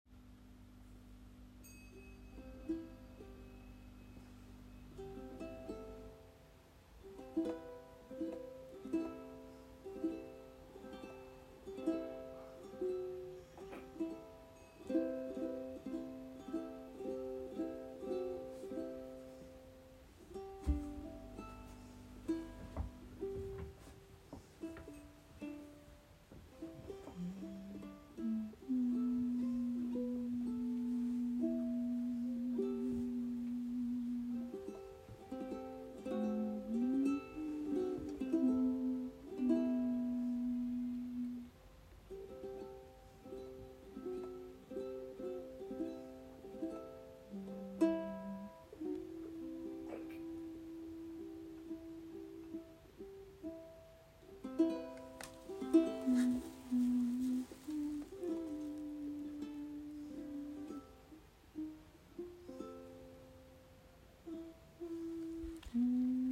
A quiet moment by an Ambient Jam member’s bedside
AJ-Bedside-Recording.m4a